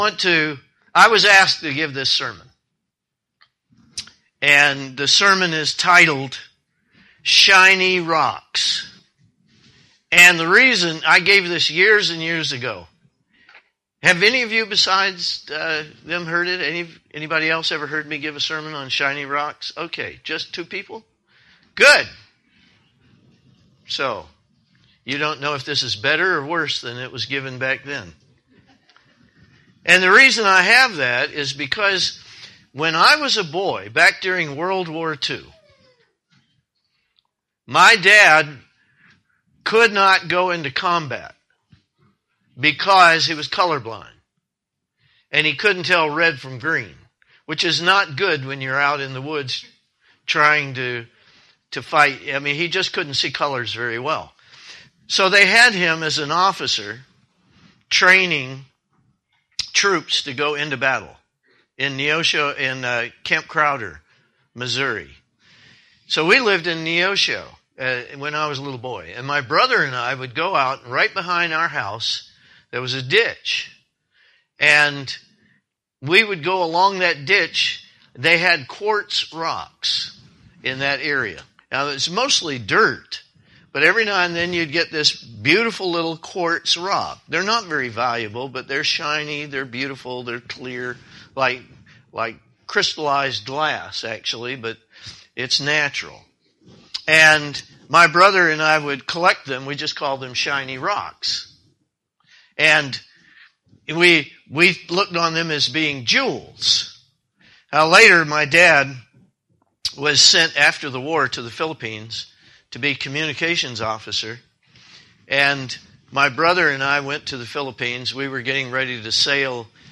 Sermons
Given in Ft. Lauderdale, FL